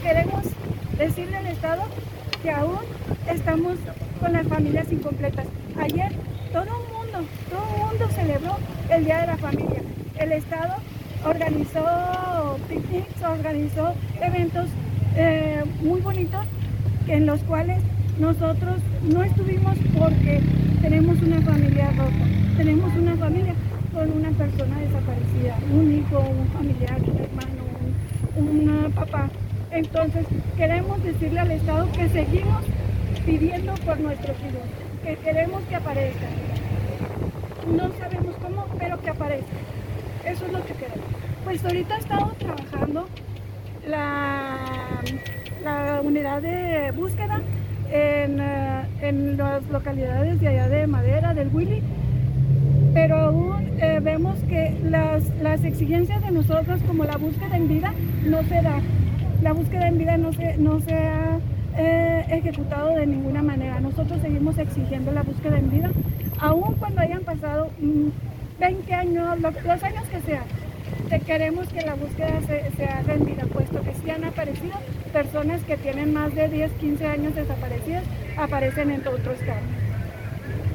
Este lunes, cerca de las 11 de la mañana, un grupo de madres de familia se reunió en Plaza Hidalgo, frente a Palacio de Gobierno.